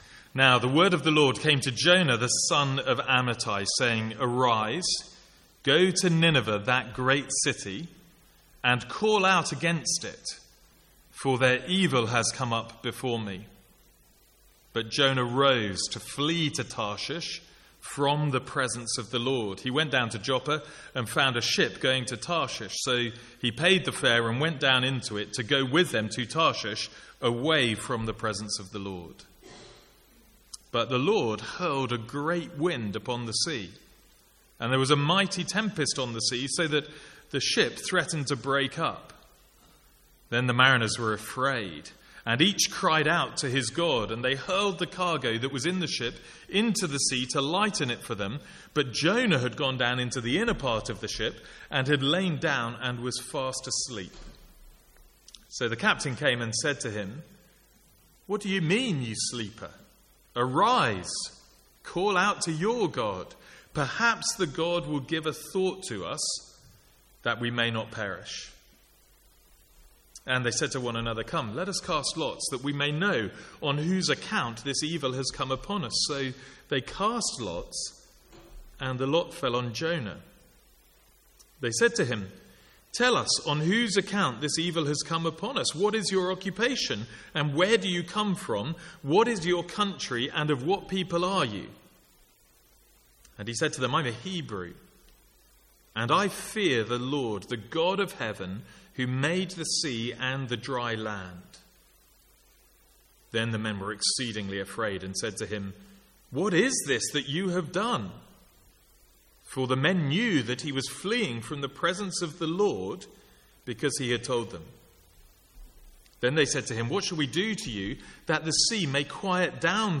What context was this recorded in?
From the Sunday evening series in Jonah.